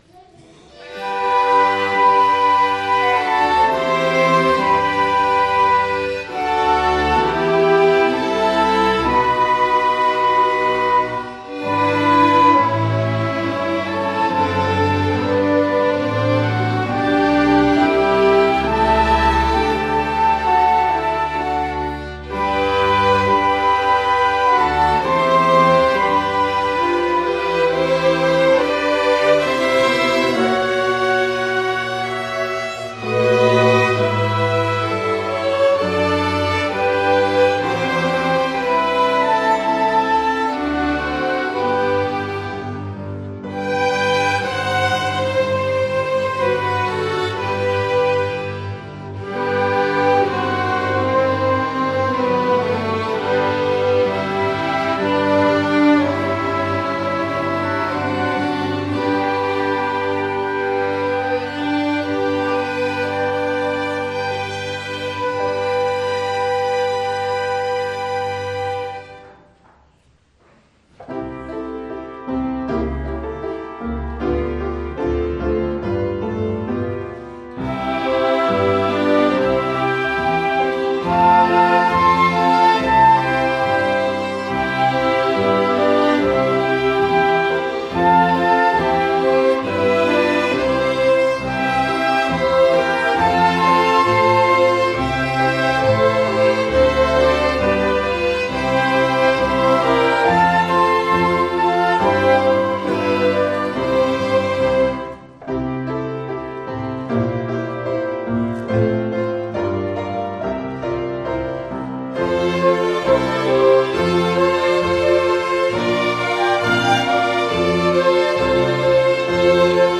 Dievkalpojums 17.01.2015: Klausīties
Svētrunas